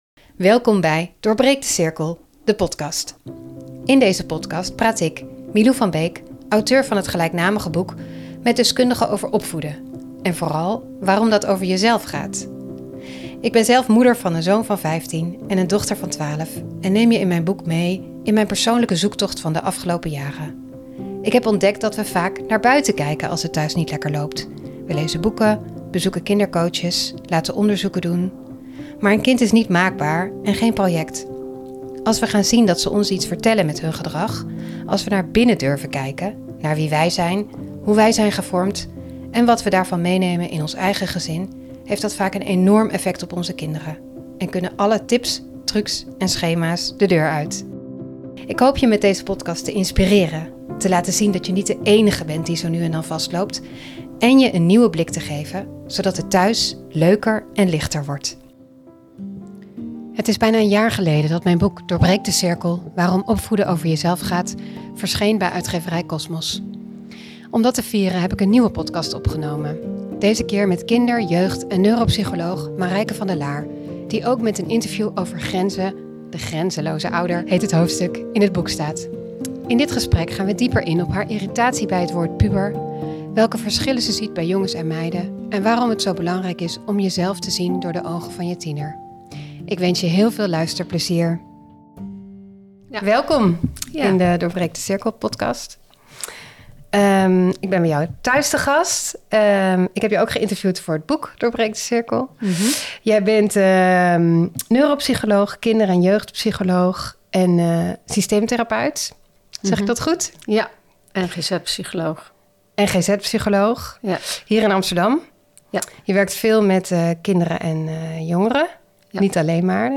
Ze gaat in deze podcast in gesprek met deskundigen over hoe je verleden jou als ouder heeft gevormd, welke patronen van generatie op generatie zijn doorgegeven en in elkaar grijpen.